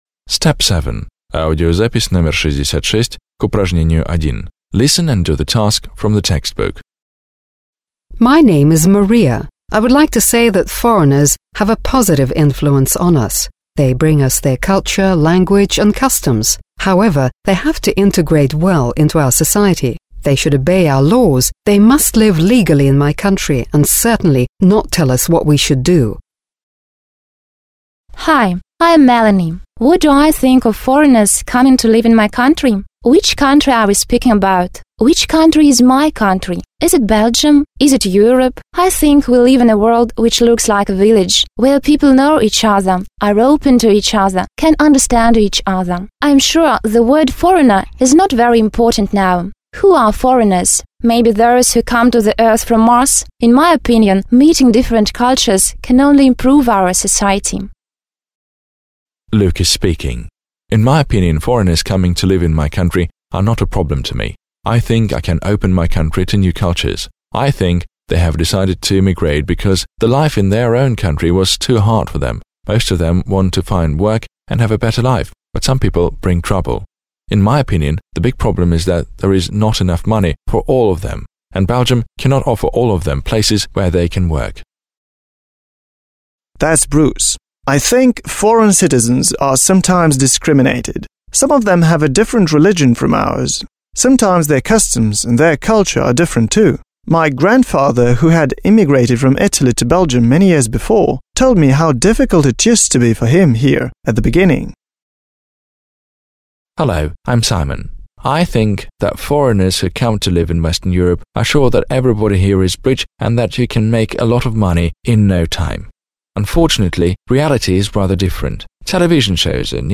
1. Listen to what six teenagers from Belgium think about immigration in their country, (66). Say who: – Послушайте, что думают шесть подростков из Бельгии об иммиграции в их стране. Скажи, кто: